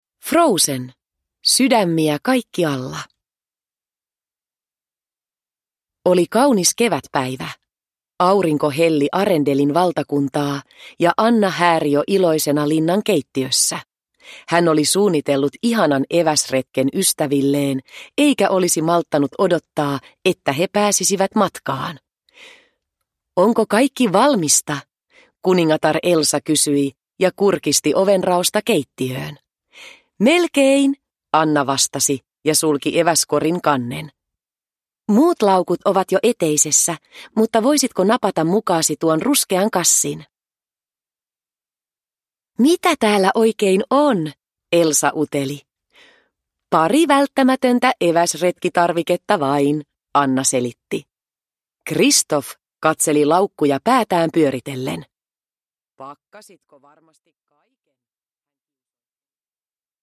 Sydämiä kaikkialla – Ljudbok – Laddas ner